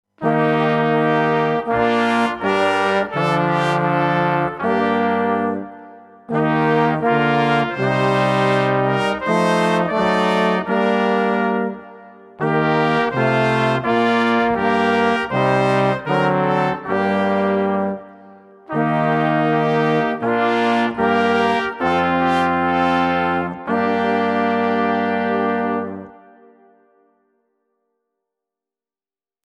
Wonderful settings of Luther songs in the typical swinging